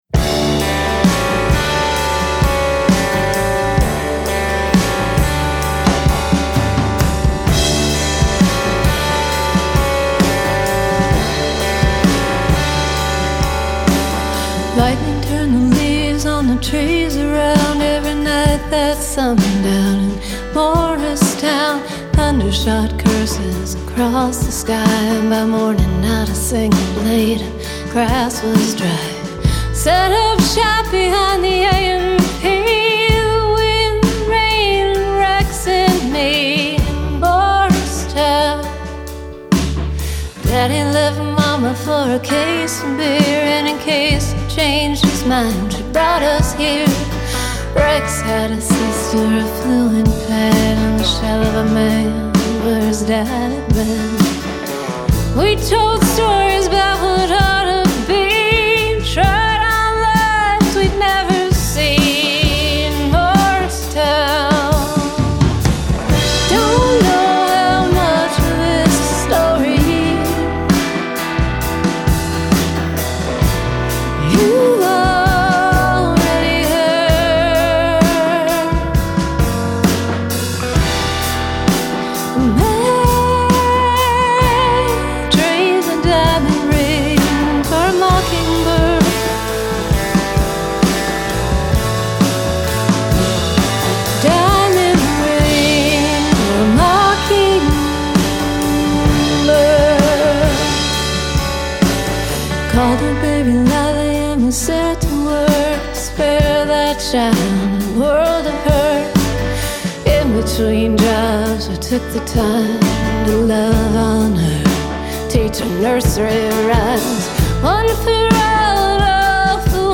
Genre: Americana